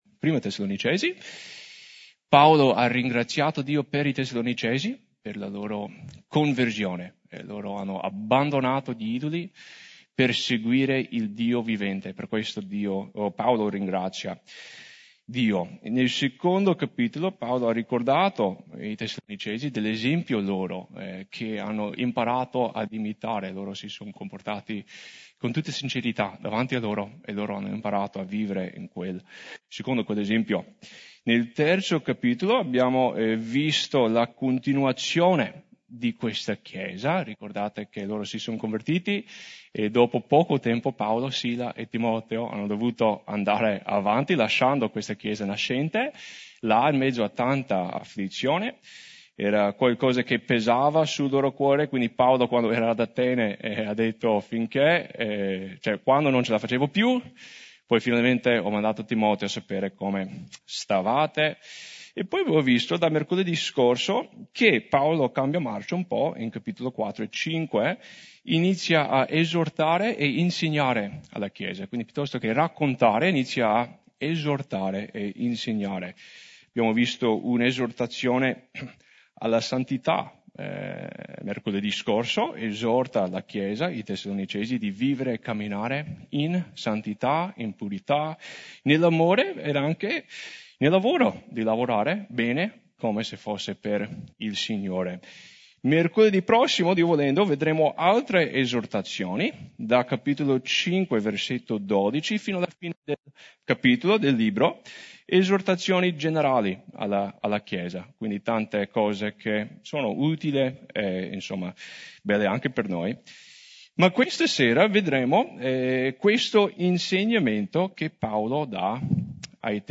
Studio biblico di Mercoledì 4 Marzo